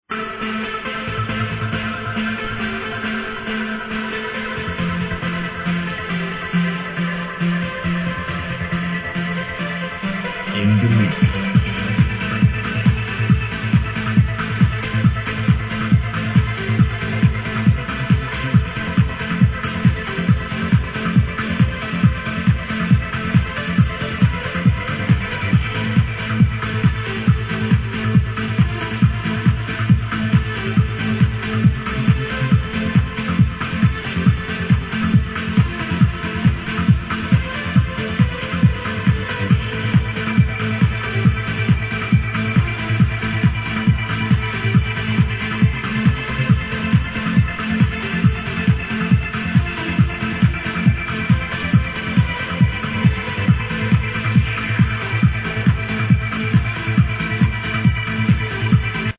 Please help me to ID this trance tune!!